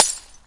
玻璃碎片3
描述：短暂的啤酒瓶破碎。 用Zoom H1记录。
Tag: 玻璃 粉碎 破碎 破碎 玻璃破碎 粉碎